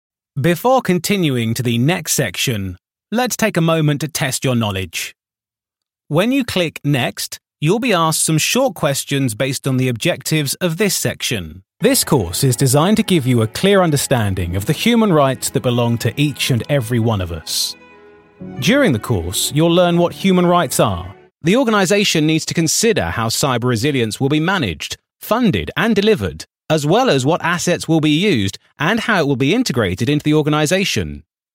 E-learning